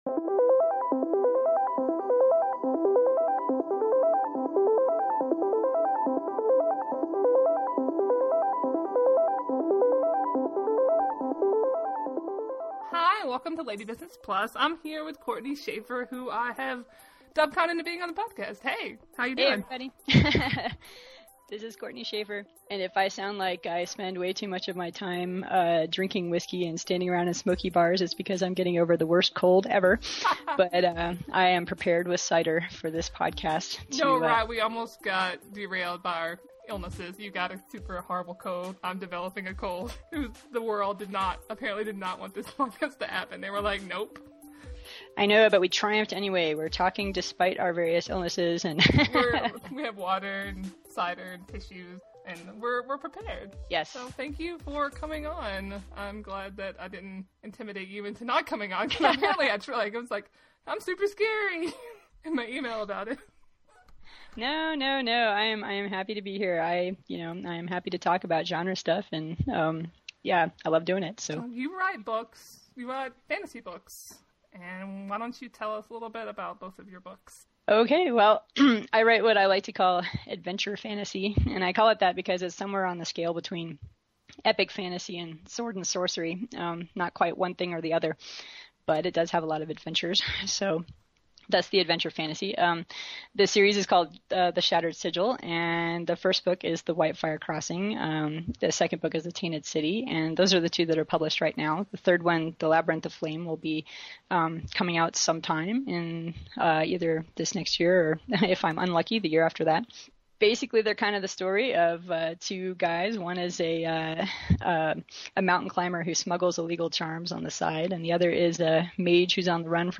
Disclaimer and warning: this episode was recorded five thousand years ago in Internet time and both of us were suffering from the plague.